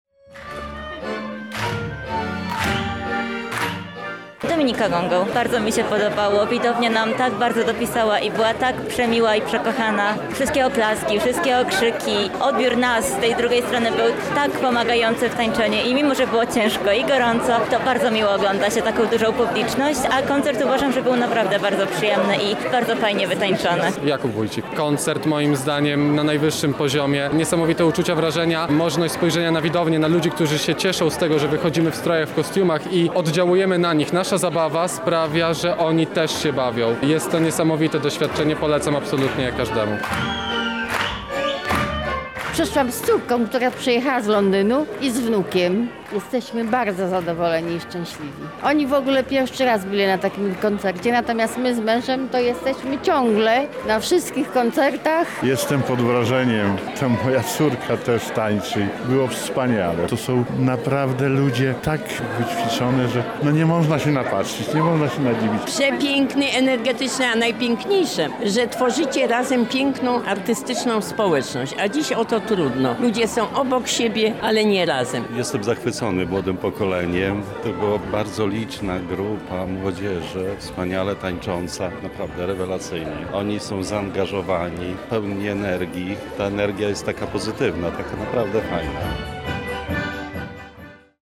Posłuchajmy relacji, w której swoimi emocjami dzielą się zarówno artyści, jak i widzowie koncertu:
Koncert Noworoczny ZTL UMCS, 25.01